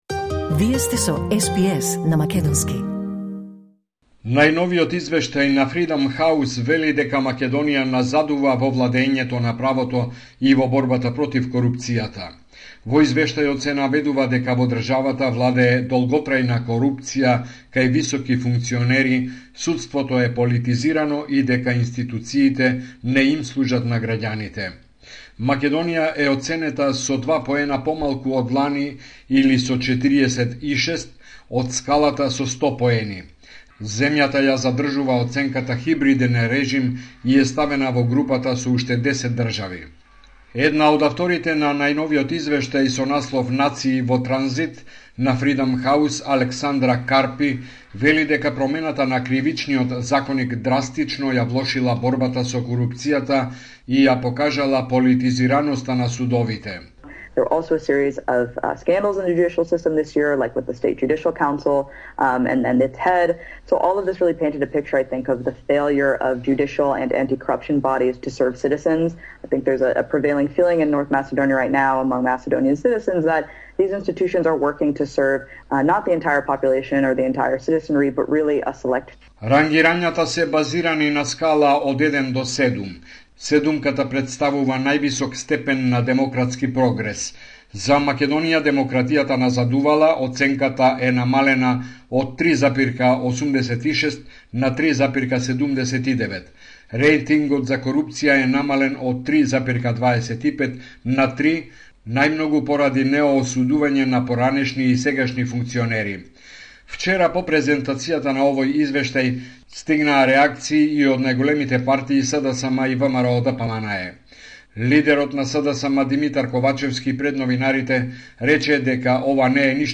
Homeland Report in Macedonian 12 April 2024